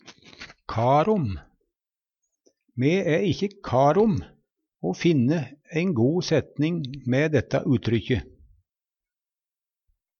o væra kar om - Numedalsmål (en-US)
Tilleggsopplysningar Kan og brukast: Å væra kar te Hør på dette ordet Ordklasse: Uttrykk Kategori: Uttrykk Karakteristikk Attende til søk